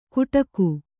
♪ kuṭaku